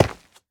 Minecraft Version Minecraft Version snapshot Latest Release | Latest Snapshot snapshot / assets / minecraft / sounds / block / basalt / break5.ogg Compare With Compare With Latest Release | Latest Snapshot
break5.ogg